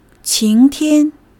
qing2--tian1.mp3